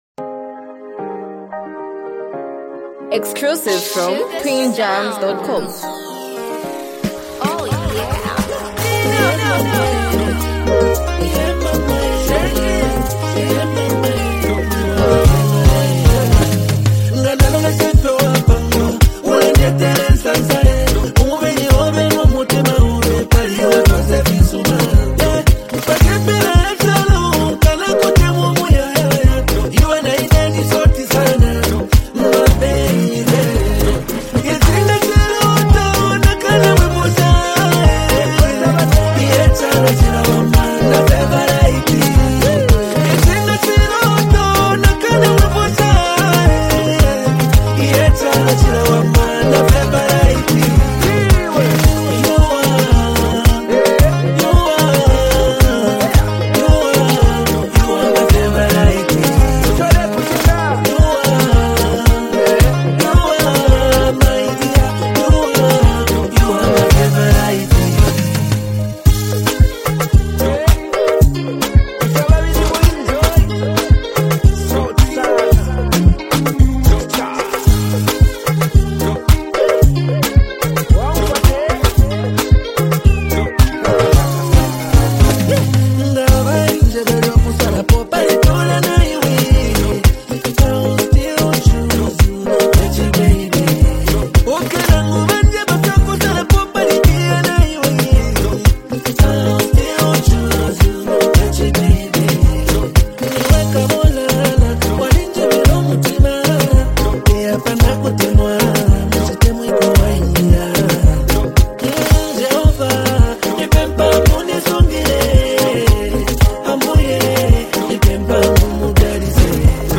smooth rhythms with catchy hooks